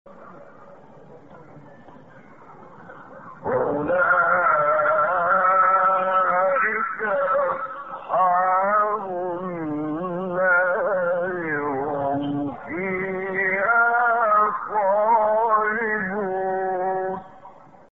گروه فعالیت‌های قرآنی: فرازهایی در مقام صبا با صوت محمد عمران ارائه می‌شود.
برچسب ها: خبرگزاری قرآن ، ایکنا ، فعالیت های قرآنی ، مقام صبا ، محمد عمران ، قاری مصری ، فراز صوتی ، نغمه ، قرآن ، iqna